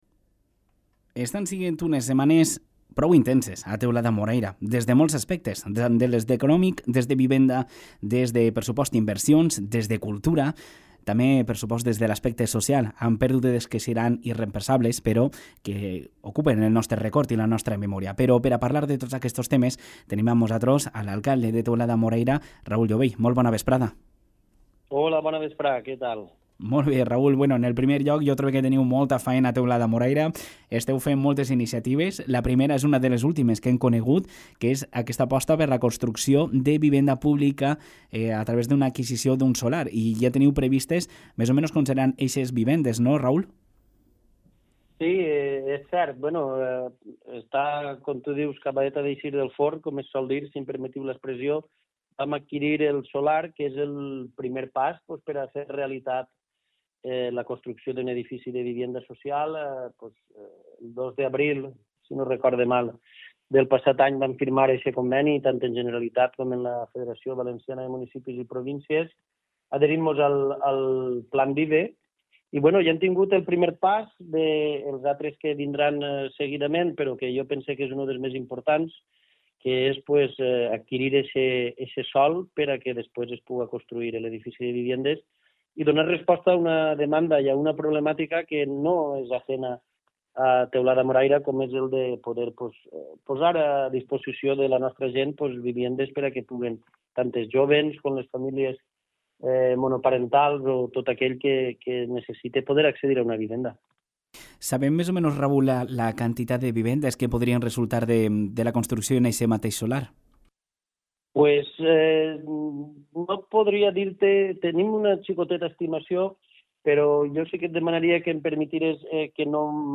Por ello, hemos podido conversar con Raúl Llobell, alcalde de la localidad, quien ha destacado varias iniciativas clave que están marcando el ritmo de desarrollo en el municipio.